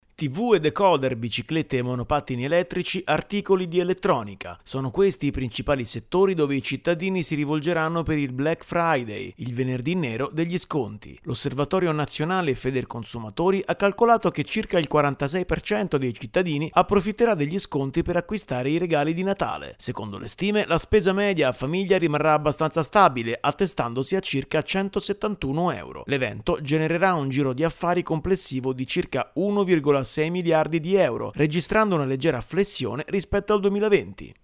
servizio_black_friday.mp3